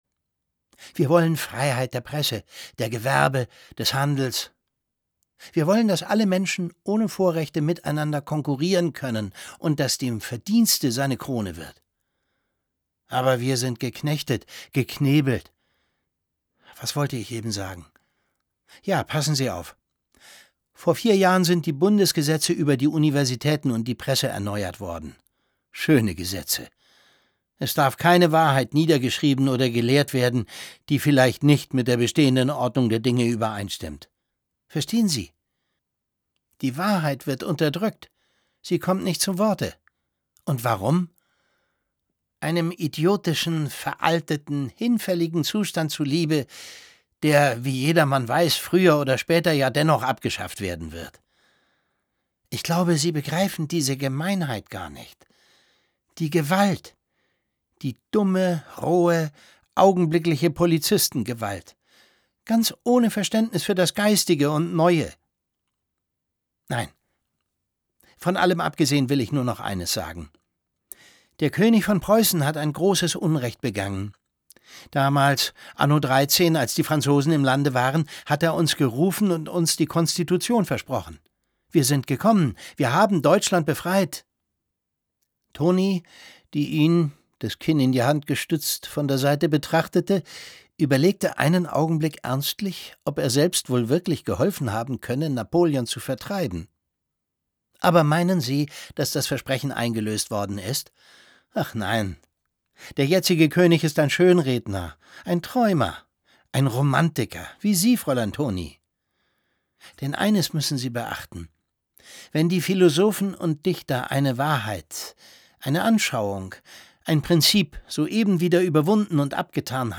Bei einem gemeinsamen Ausflug zum Seetempel, einem Pavillon mit herrlicher Aussicht aufs Meer, schildert er leidenschaftlich seine Vision einer gerechten Gesellschaft: ohne Privilegien des Adels, mit gleichen Rechten für alle Bürger. Es liest Thomas Sarbacher.